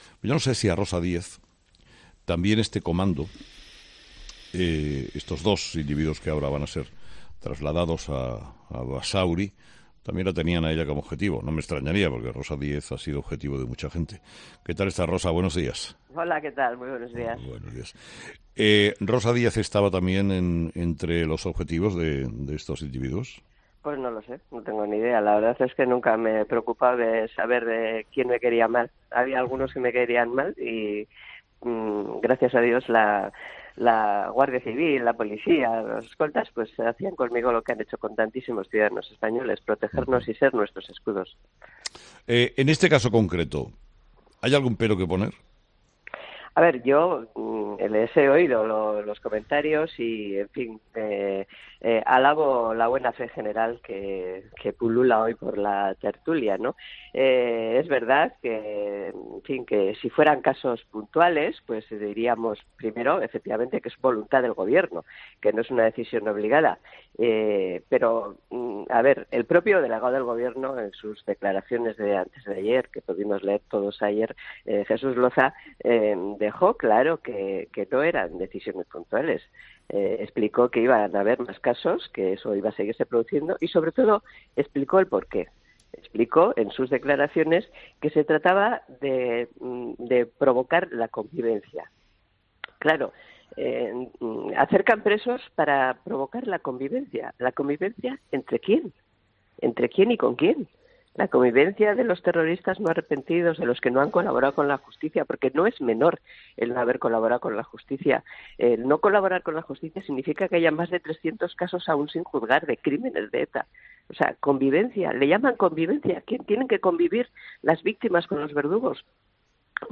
Rosa Díez, la exdiputada y víctima de un intento de atentado del comando Vizcaya de la banda terrorista ETA, ha participado en 'Herrera en COPE' para comentar la decisión del Gobierno de acercar a los presos etarras al País Vasco, bajo el pretexto de promover la convivencia.